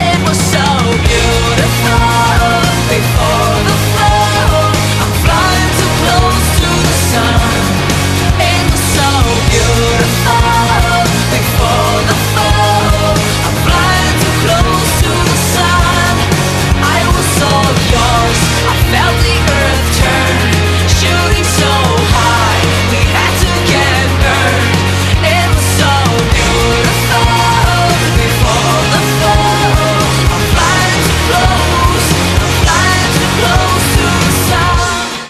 • Качество: 192, Stereo
очень романтичная и красивая